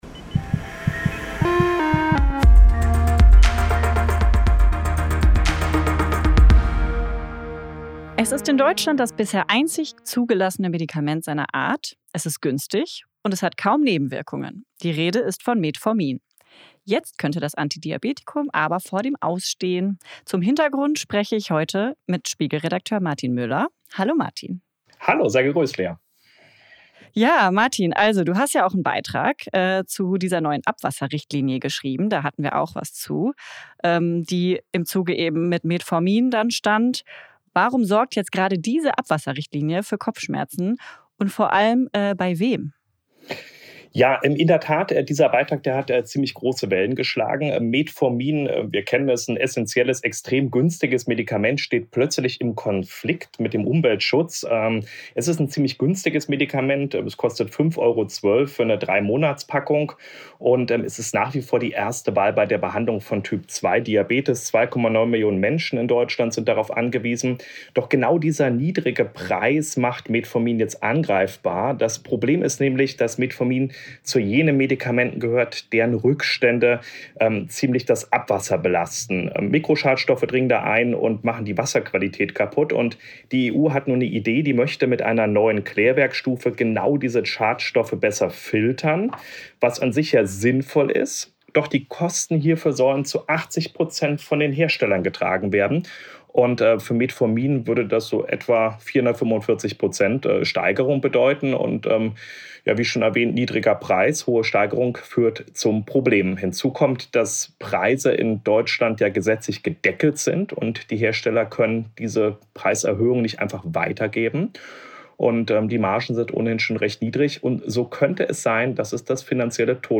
Ein Gespräch mit